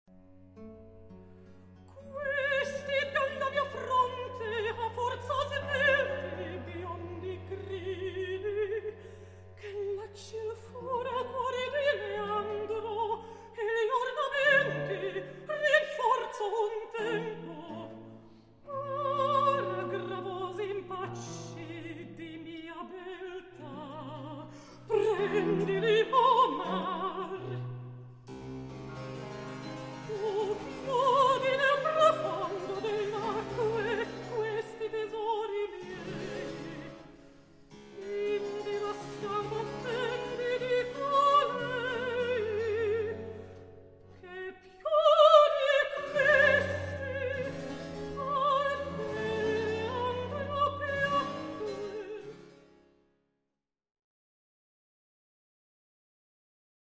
baroque repertoire